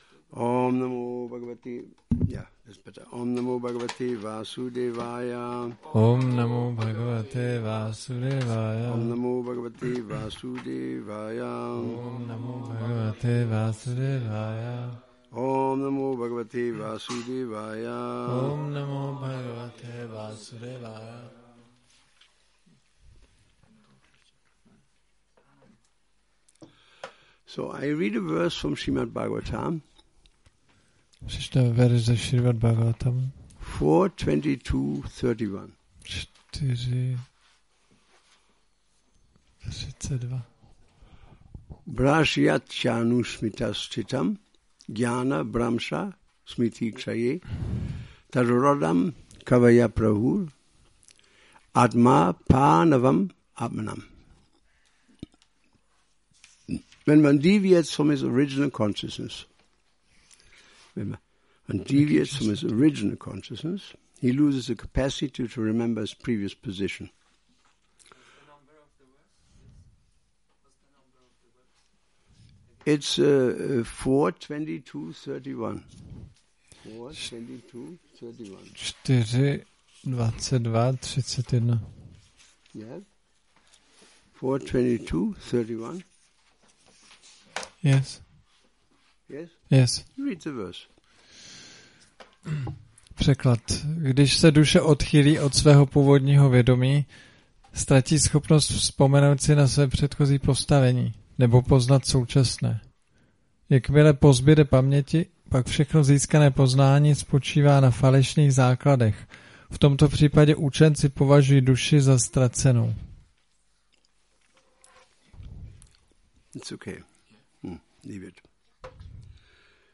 Šrí Šrí Nitái Navadvípačandra mandir
Přednáška SB-4.22.31